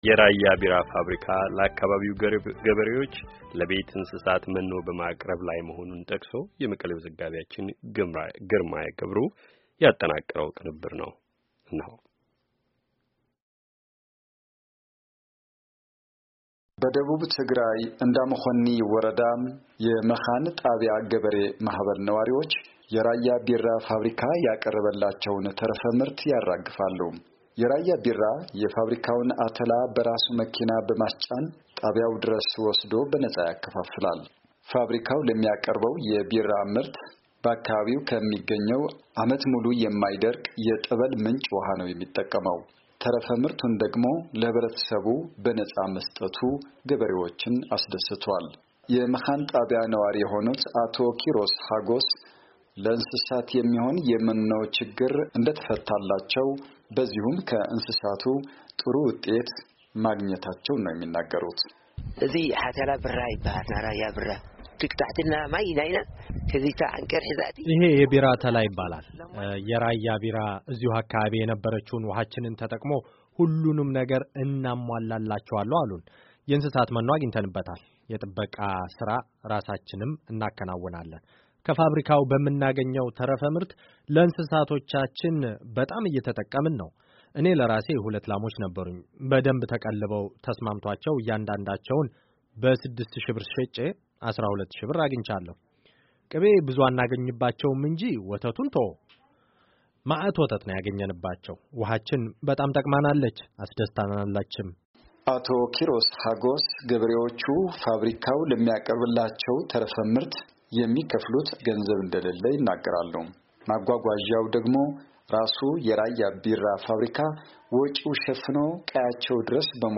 ገበሬዎቹን አነጋግሮ ያጠናቀረውን ዘገባ